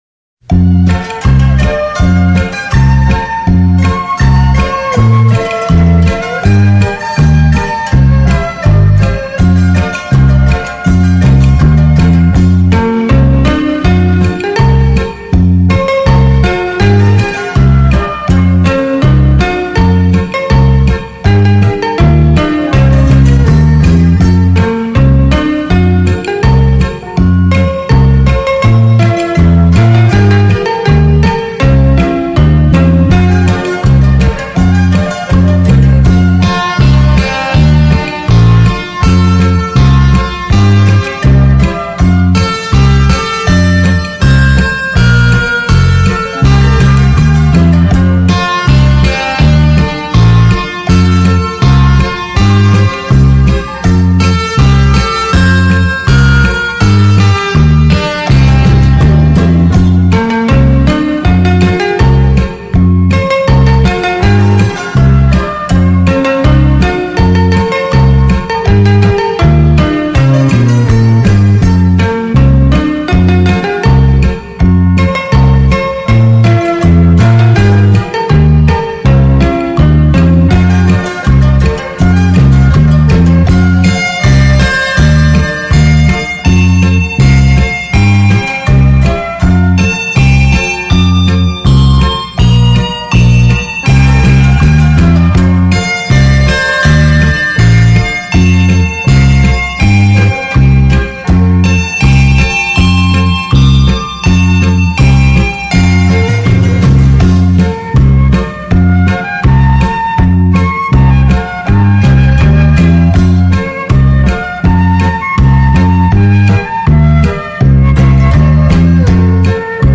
专辑格式：DTS-CD-5.1声道
也没有流行音乐的喧嚣，
充满其中的是无法言语的清新